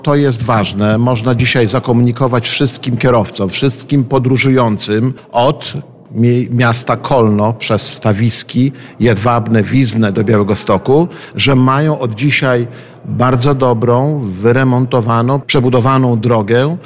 Starosta Lech Szabłowski podkreślił, że to bardzo ważna inwestycja.